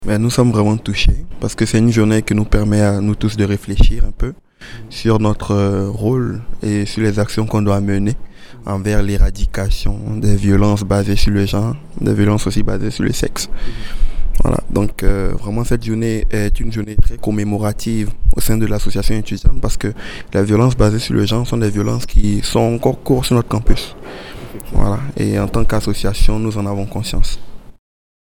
Dans le cadre de la Journée nationale de commémoration et d’action contre la violence faite aux femmes, le Campus Saint-Jean et la Coalition des Femmes de l’Alberta ont ténus une Cérémonie d’Hommage communautaire aux Victimes du Féminicide du 6 décembre 1989, qui s’est déroulé le 6 décembre 2022 au Pavillon McMahon du Campus Saint-Jean de l’Université de l’Alberta.
Le 6 décembre a été une journée marquée par une cérémonie d’hommage aux 14 victimes du féminicide de l’école Polytechnique.